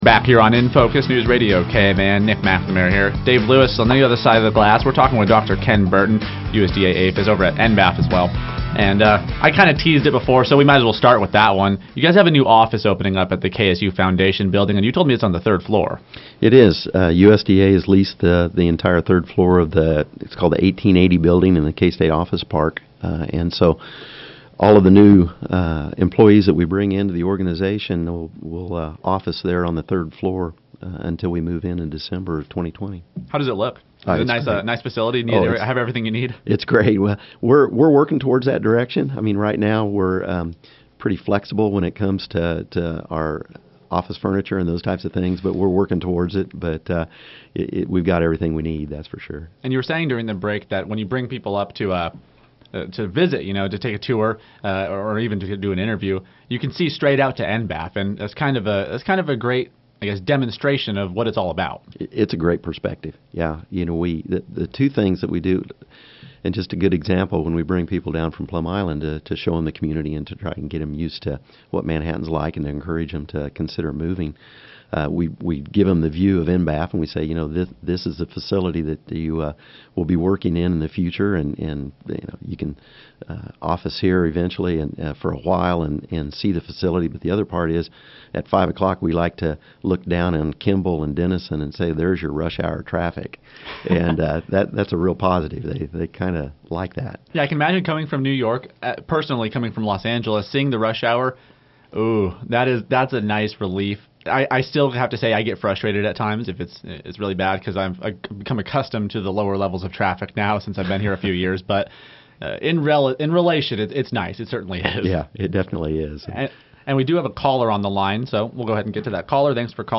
We were also joined over the phone by Governor’s Military Council Executive Director Perry Wiggins. He discussed the recent federal military budget and the importance of maintaining technological supremacy over competing nations.